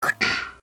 falling-block.ogg